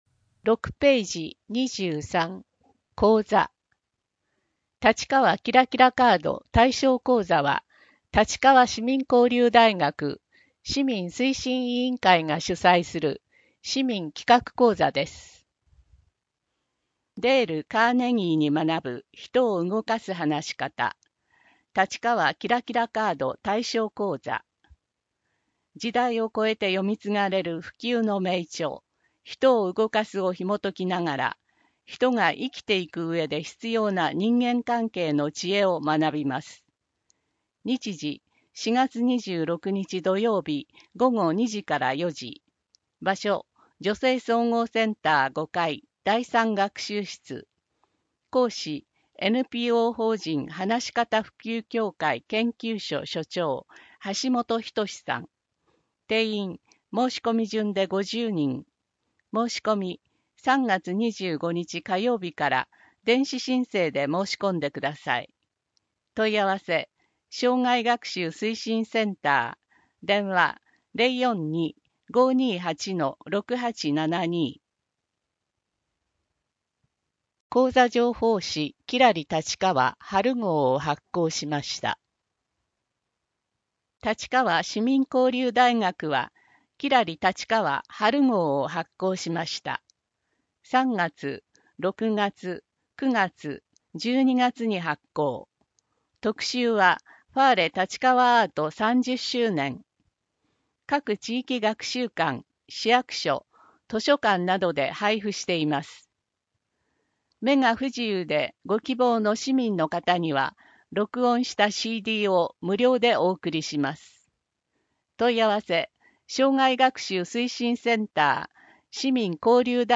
MP3版（声の広報）